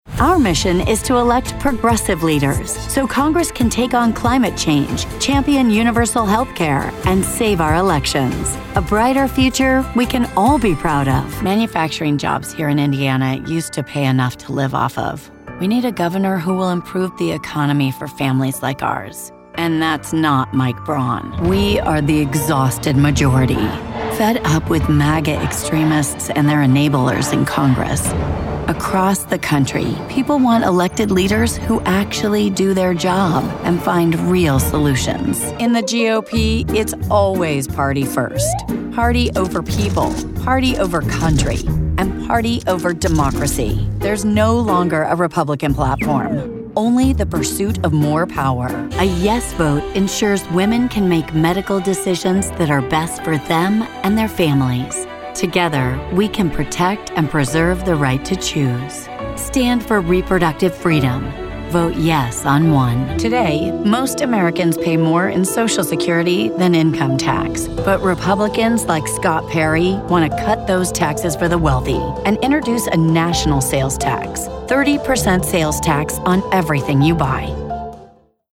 Political Demo.